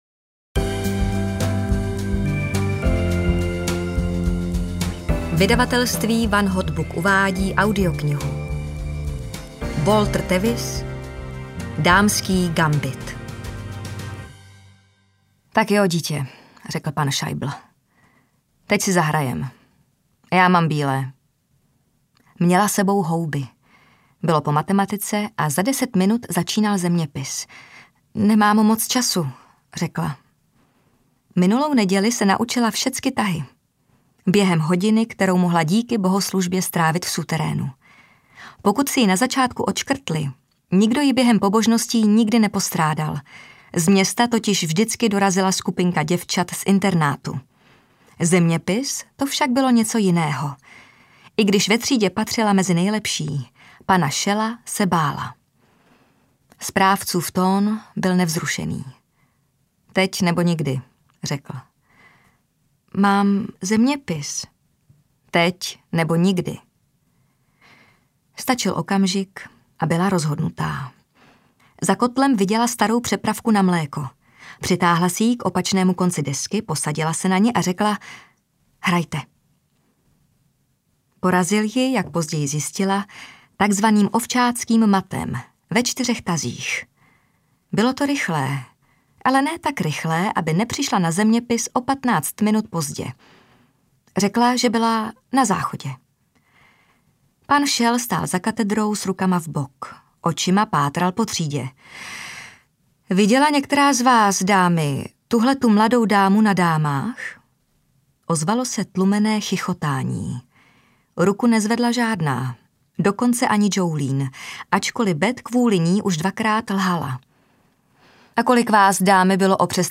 Dámský gambit audiokniha
Ukázka z knihy